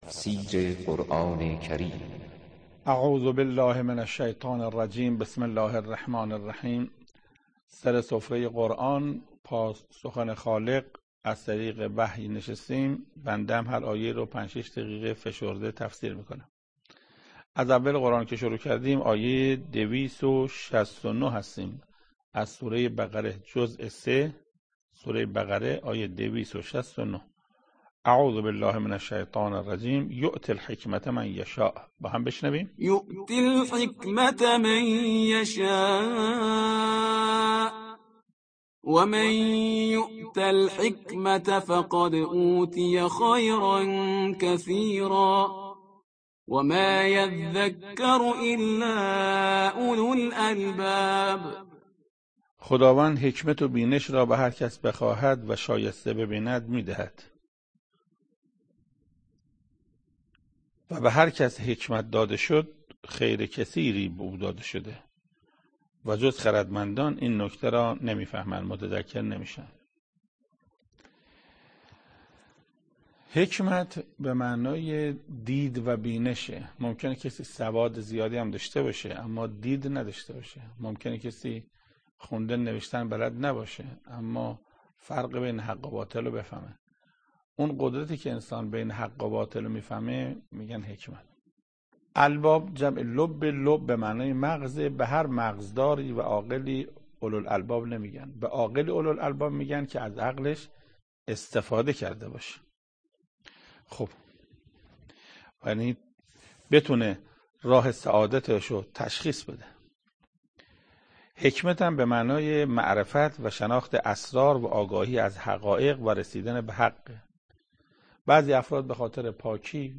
تفسیر دویست و شصت و نهمین آیه از سوره مبارکه بقره توسط حجت الاسلام استاد محسن قرائتی به مدت 8 دقیقه